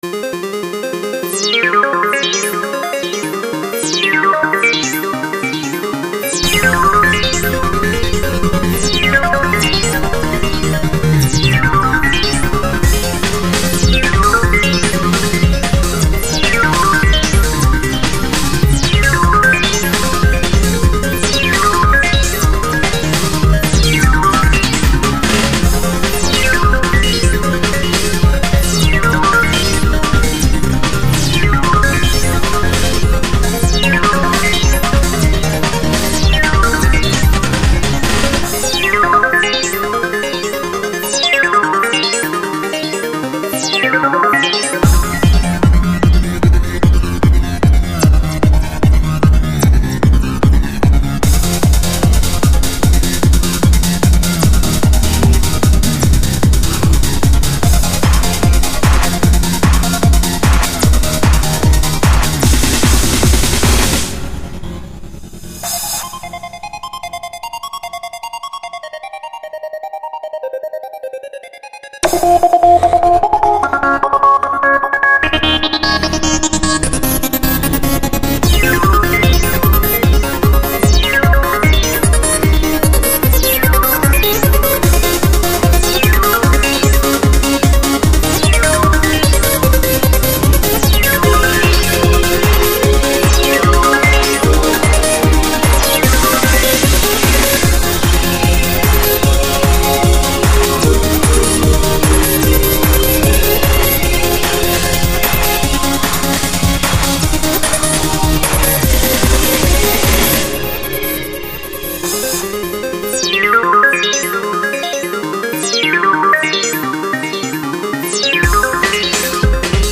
An instrumental recording.